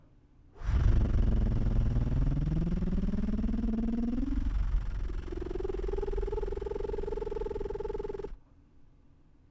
参考音源：失敗パターン
音量注意！
その状態で地声を一気に手放してしまうと↑の音源のように声にならない部分がでてきます。